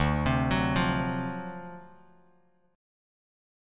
D7#9 chord